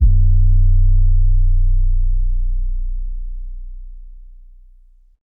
long 808.wav